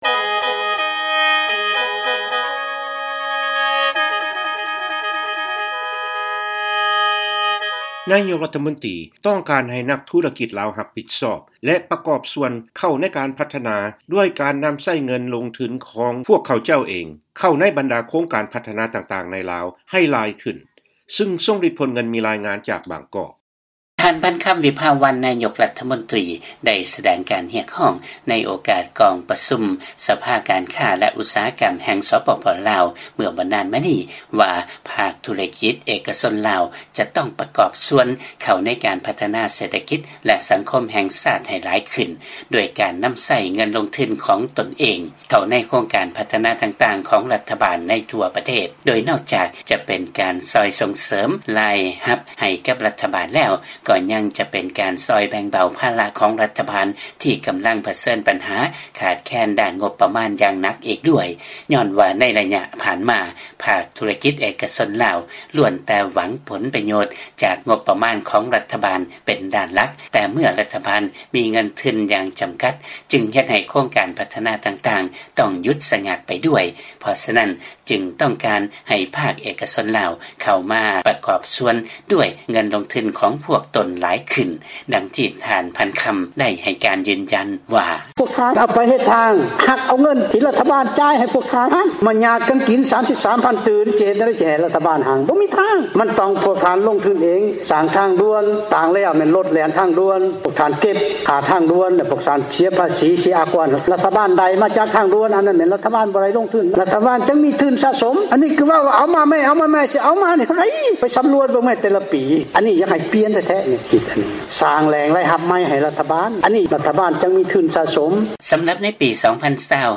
ລາຍງານຈາກບາງກອກ.
ເຊີນຟັງລາຍງານ ນາຍົກລາວ ຕ້ອງການໃຫ້ນັກທຸລະກິດລາວ ຮັບຜິດຊອບແລະປະກອບສ່ວນ ໃນການພັດທະນາ ດ້ວຍການລົງທຶນໃນໂຄງການຂອງລັດ